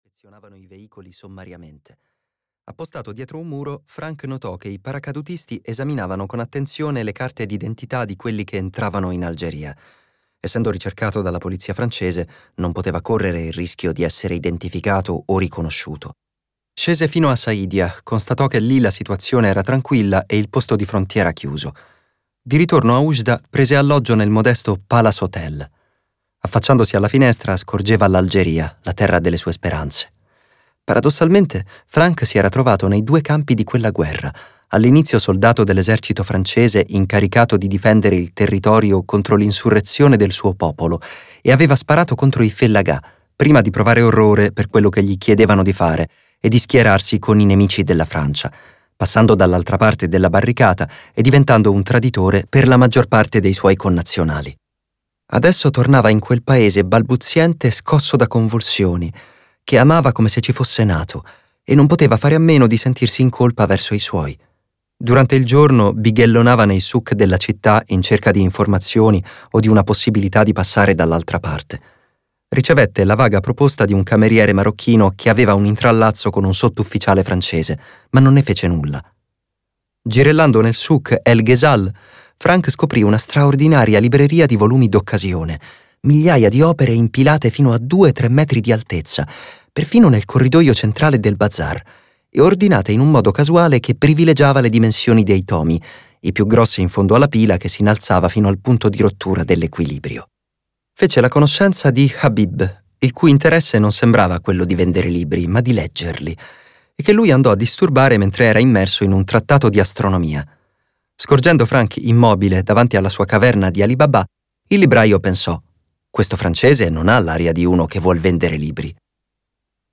"Le Terre promesse" di Jean-Michel Guenassia - Audiolibro digitale - AUDIOLIBRI LIQUIDI - Il Libraio